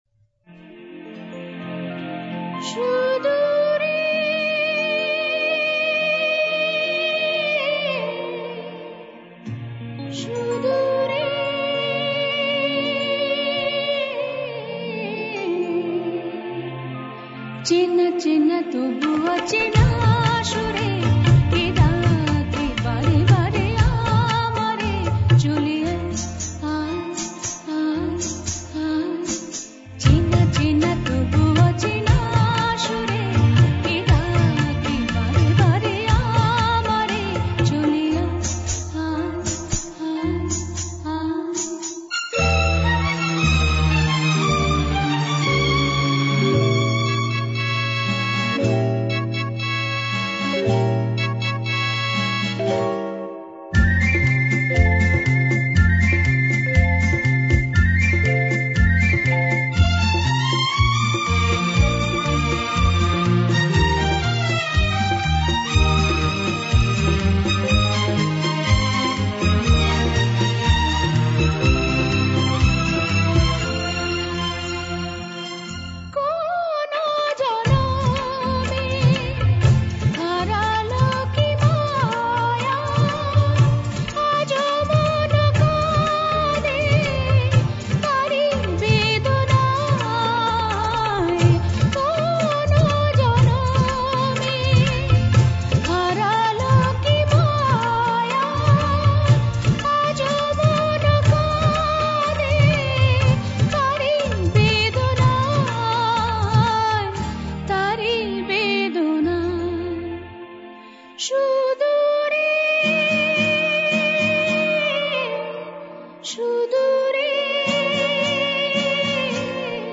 She has a wonderful voice full of expression and warmth.